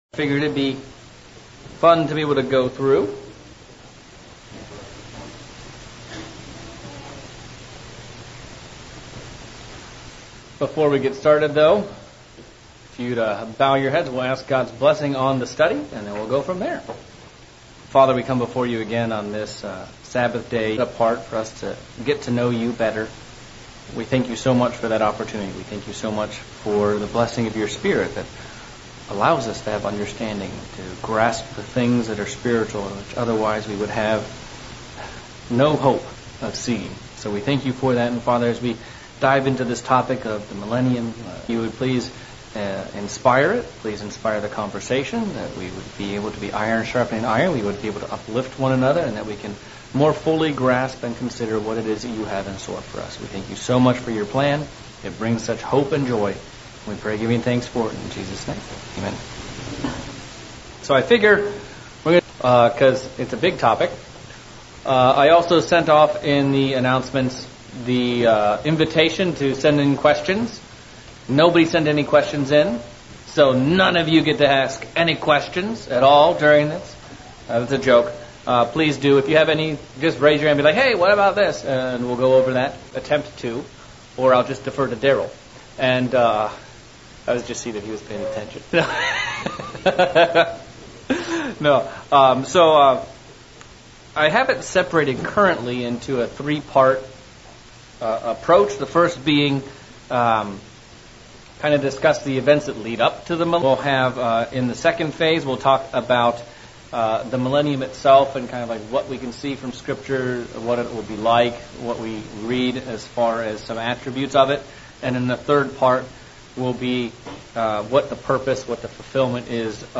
Starting a new series on the Millennium and the Kingdom of God. Pre Feast Bible Study